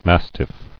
[mas·tiff]